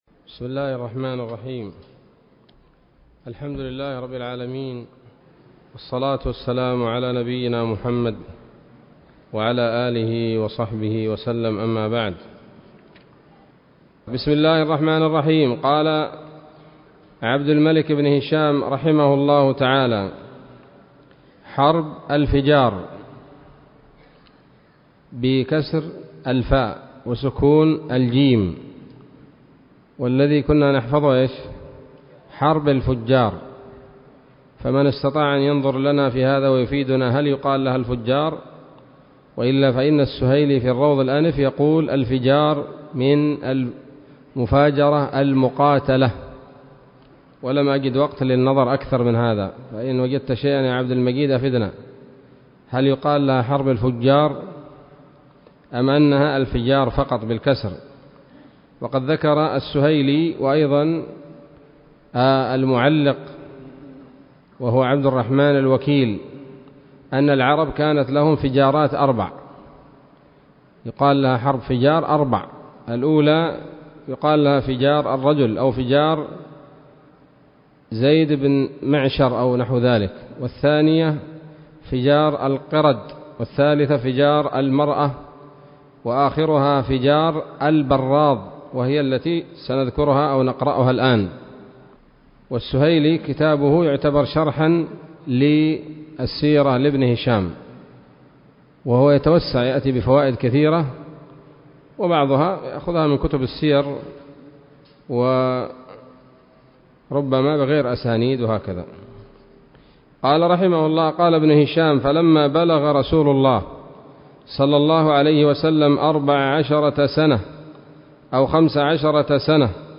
الدرس الخامس عشر من التعليق على كتاب السيرة النبوية لابن هشام